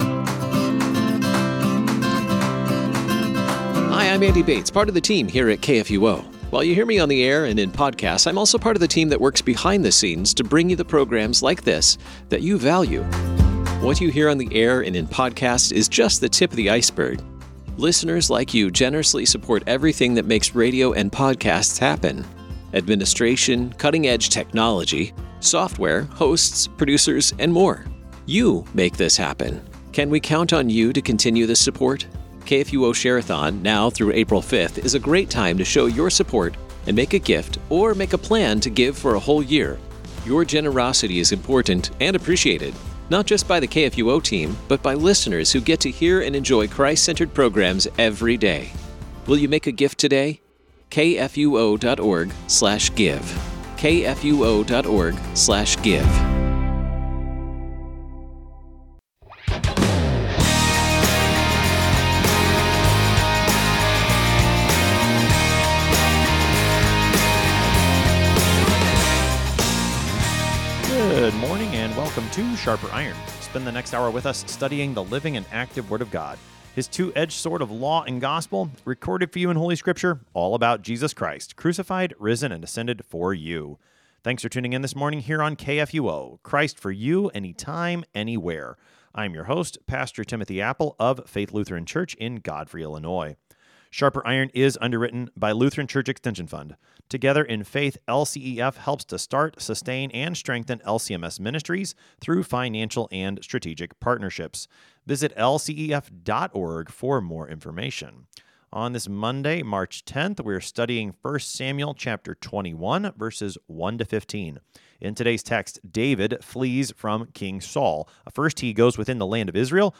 Two pastors engage with God’s Word to sharpen not only their own faith and knowledge, but the faith and knowledge of all who listen.